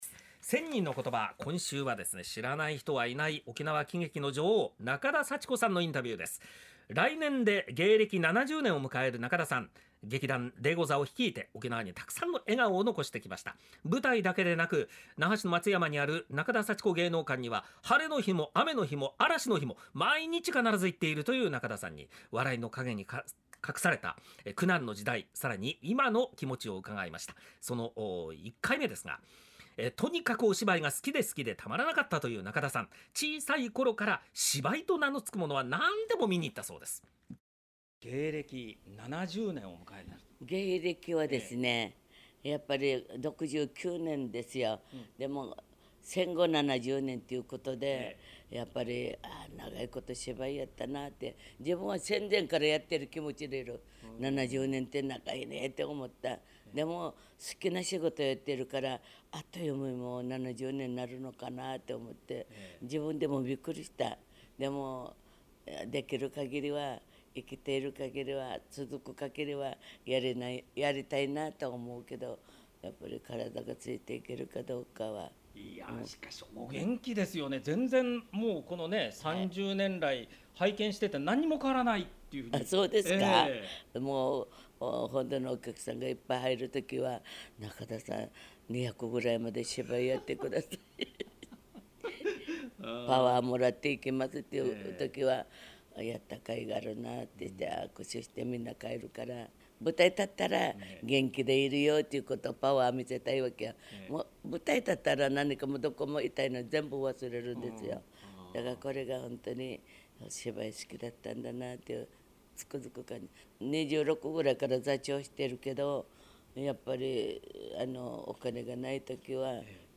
ついに登場！　沖縄喜劇の女王　仲田　幸子　さん